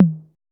808TOM1 MID.wav